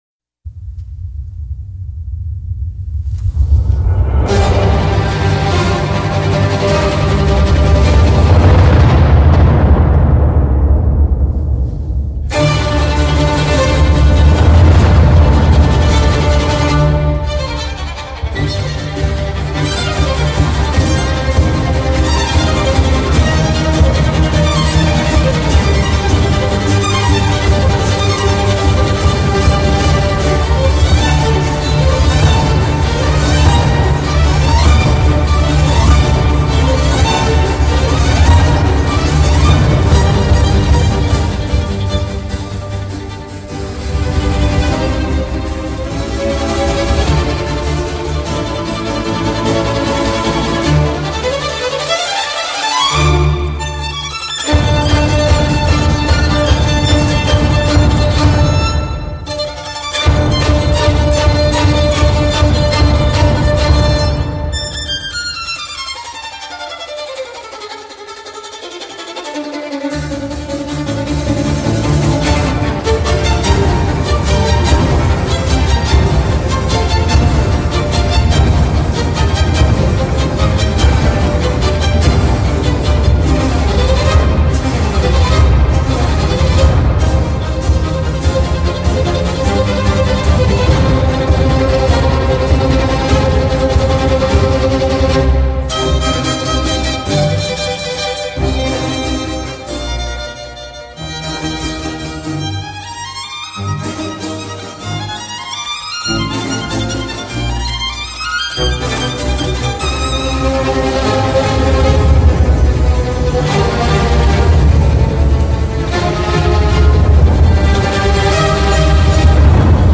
为低音质MP3